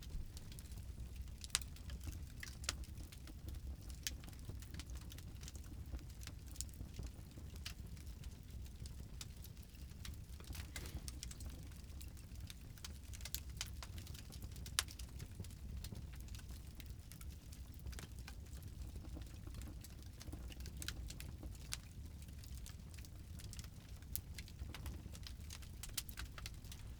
fire-flames-crackle.ogg